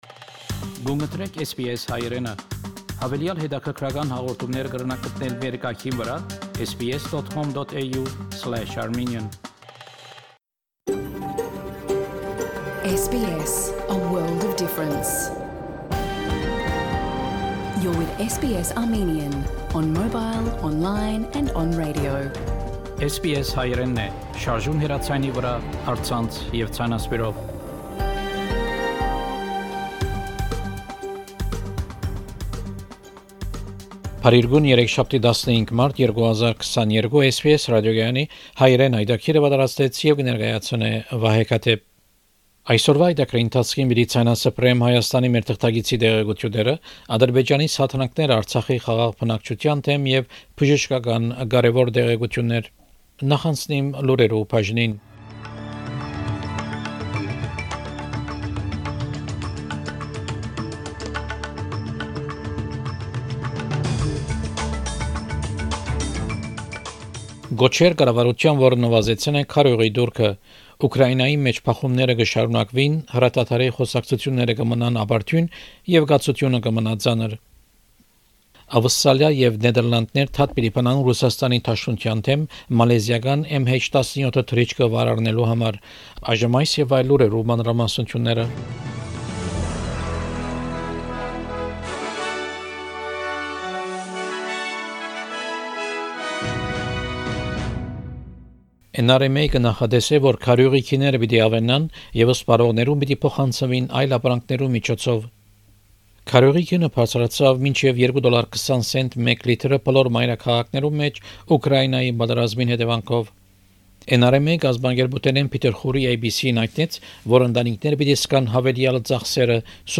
SBS Armenian news bulletin from 15 March 2022 program.